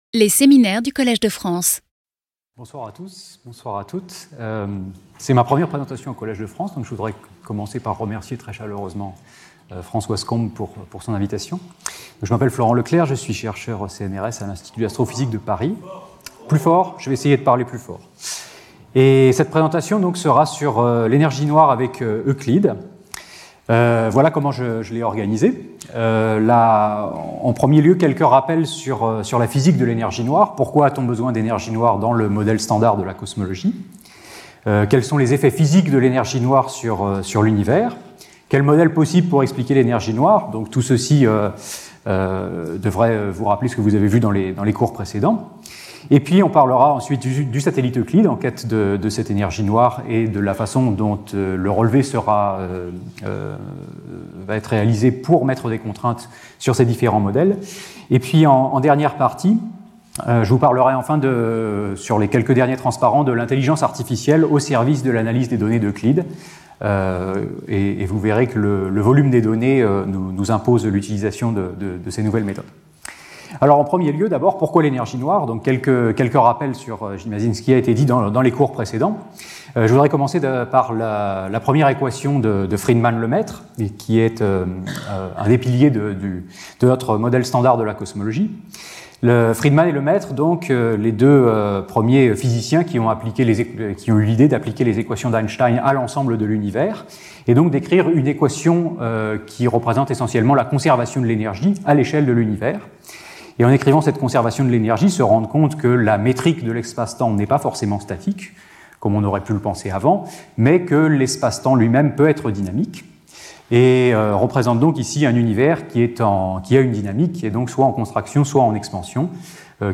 Skip youtube video player Listen to audio Download audio Audio recording Abstract One of the major frontiers of contemporary cosmology is to elucidate the nature of dark energy, responsible for a mysterious phenomenon : the acceleration of the expansion of the Universe.